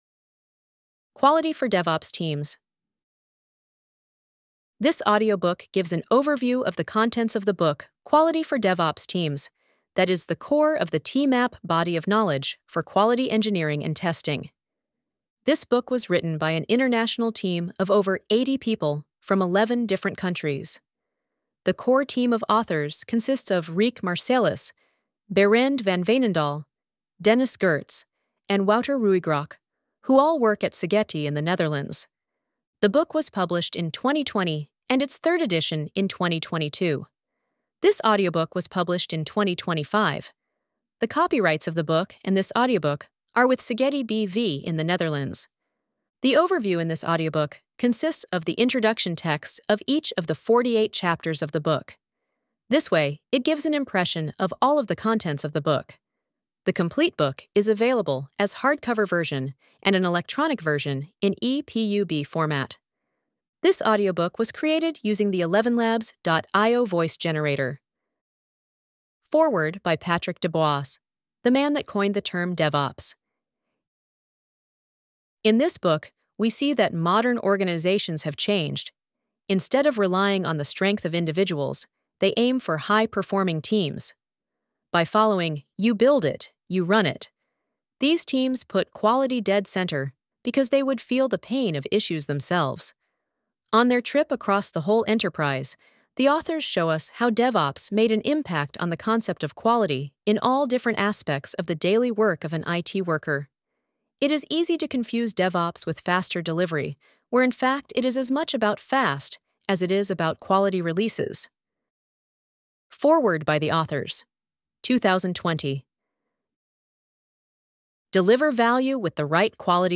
Audiobook-TMAP-Quality-for-DevOps-teams.mp3